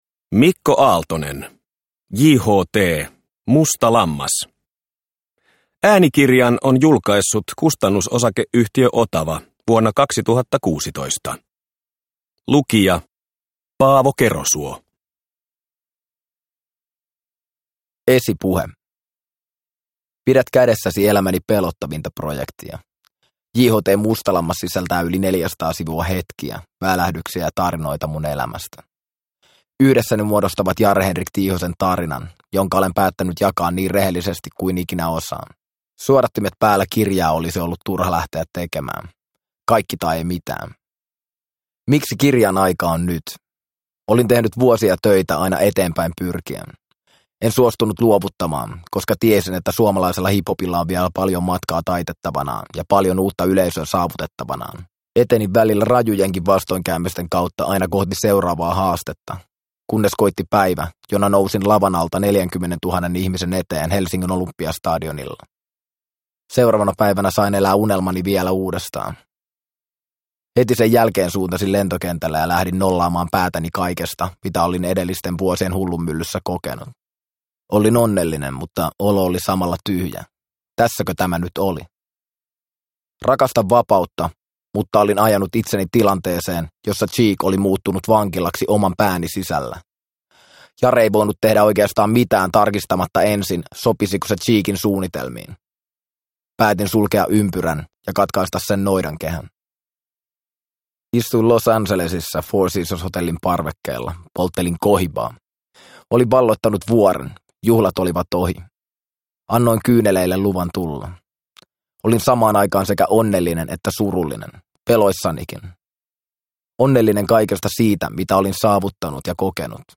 JHT – Ljudbok – Laddas ner